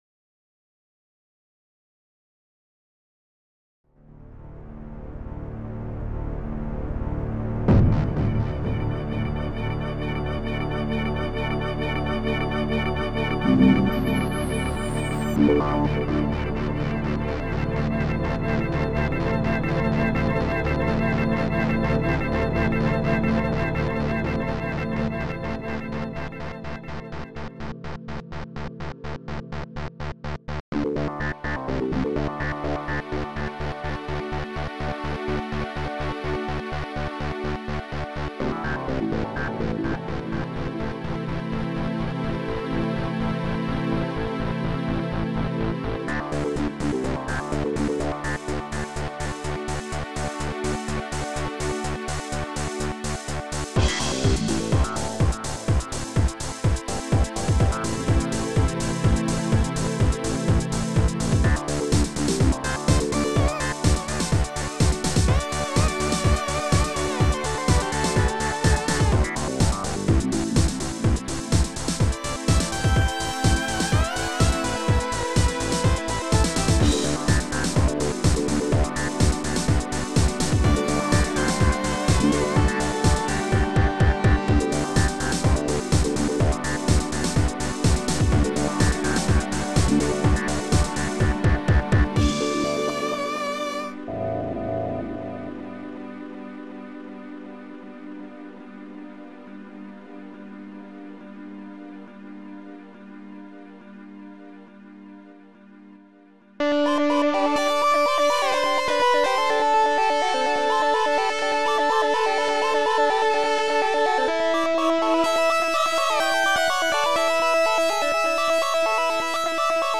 Scream Tracker Module  |  1996-03-11  |  301KB  |  2 channels  |  44,100 sample rate  |  4 minutes, 27 seconds
s3m (Scream Tracker 3)